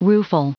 Prononciation du mot : rueful
rueful.wav